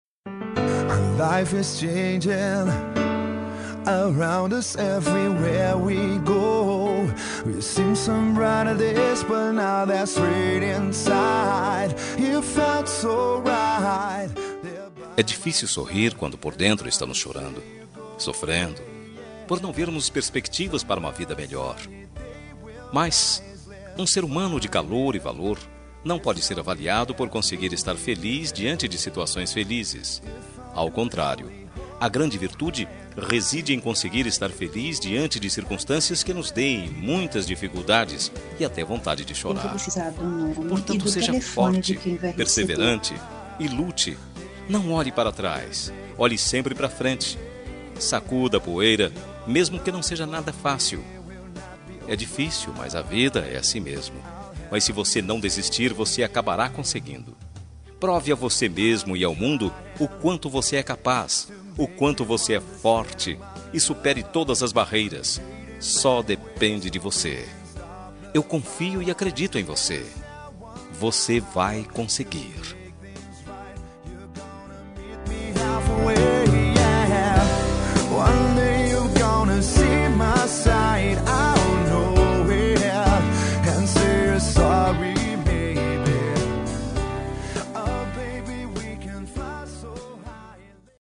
Telemensagem de Otimismo – Voz Masculina – Cód: 8081